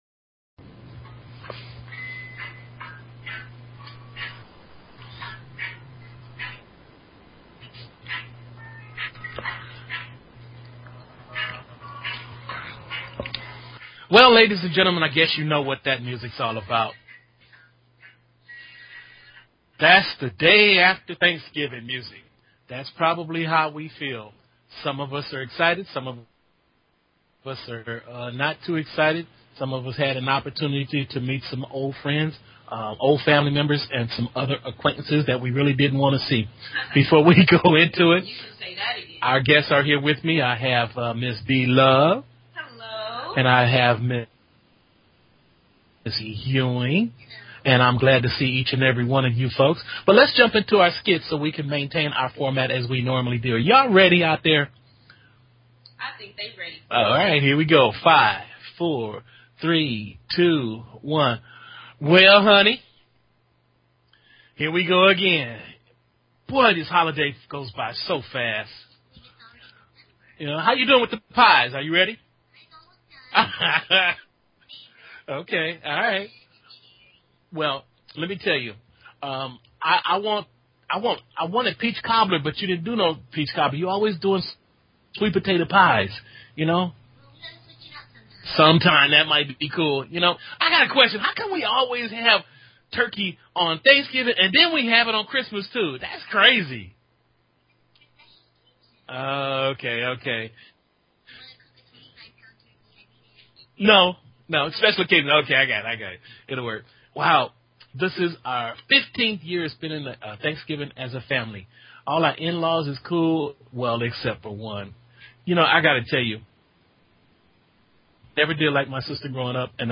Talk Show Episode, Audio Podcast, Grassroots_Talks and Courtesy of BBS Radio on , show guests , about , categorized as